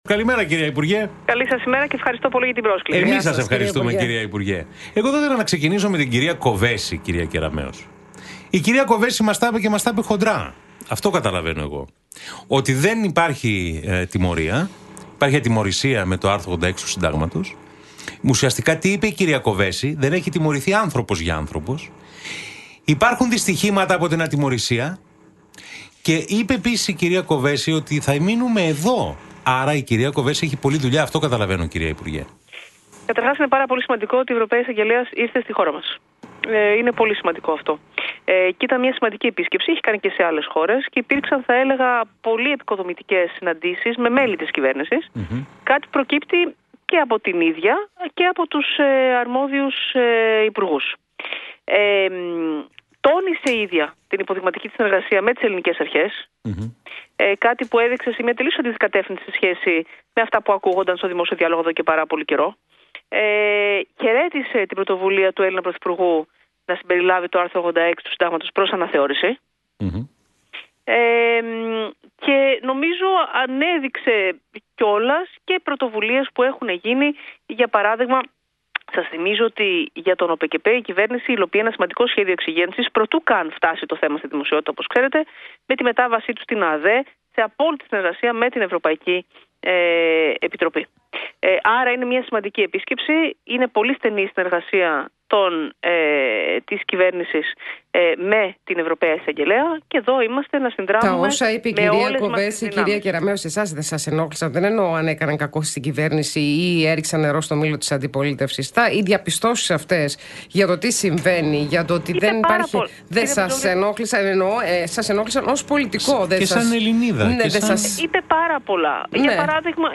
Νέες διευκρινίσεις για τη διάταξη της 13ωρης απασχόλησης που περιλαμβάνει το νέο εργασιακό νομοσχέδιο έδωσε σήμερα η υπουργός Εργασίας και Κοινωνικής Ασφάλισης, Νίκη Κεραμέως μιλώντας στον Realfm 97, 8